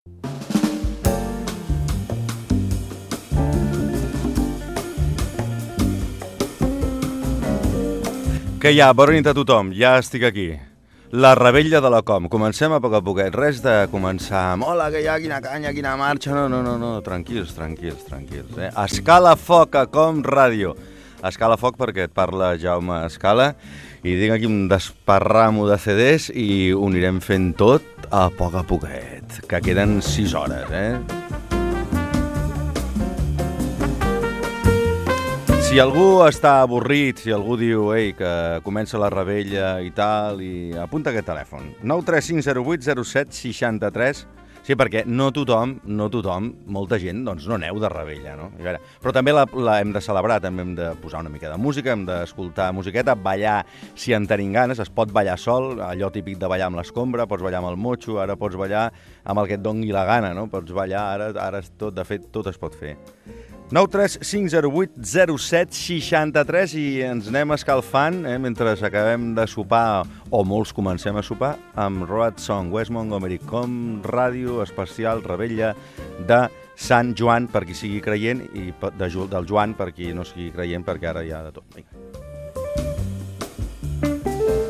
Entreteniment
FM
Fragment extret de l'arxiu sonor de COM Ràdio